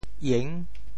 援 部首拼音 部首 扌 总笔划 12 部外笔划 9 普通话 yuán 潮州发音 潮州 iêng6 文 中文解释 援 <动> (形声。